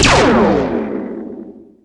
VEC3 Percussion
VEC3 Percussion 038.wav